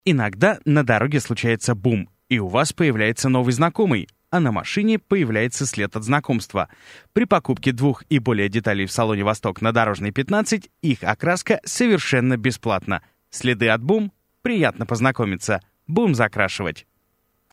Актёр.
микрофоны: Audio-Technica AT 4050 предусилители: TL-Audio Dual Valve Mic Preamp/DI конверторы: Digidesign Digi 001